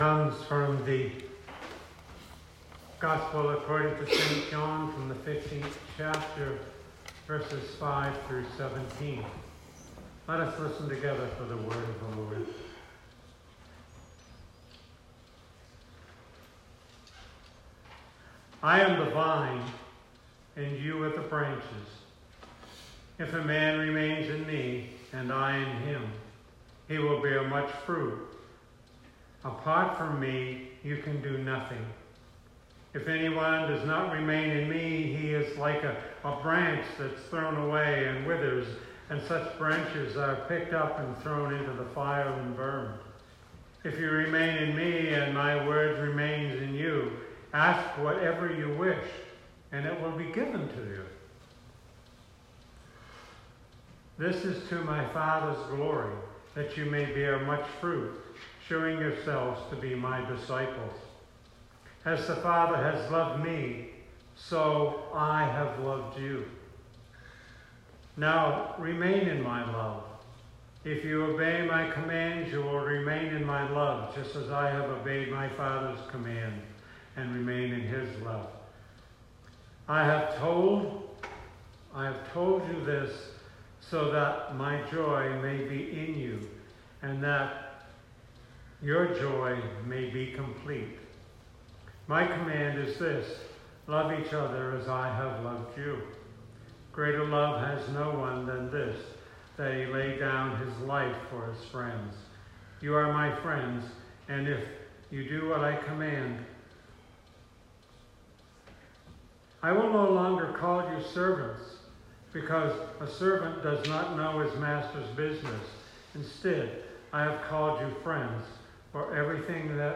Sermon 2019-09-15